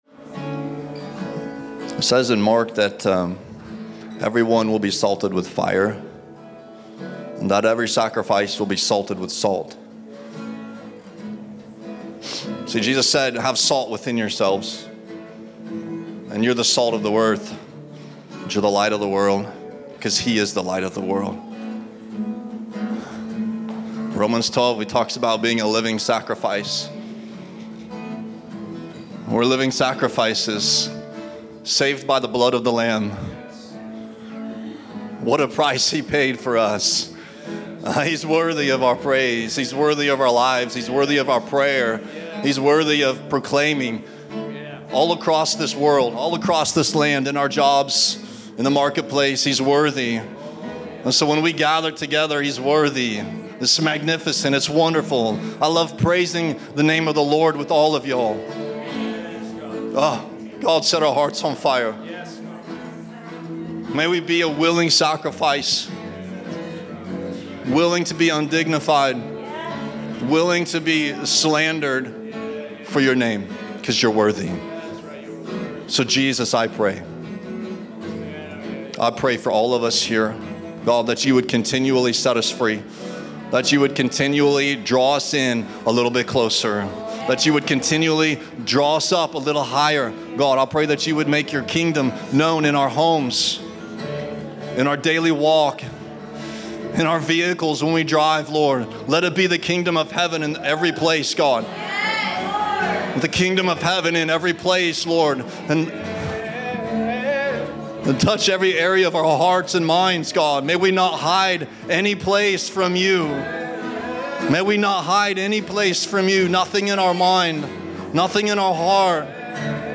Category: Exhortation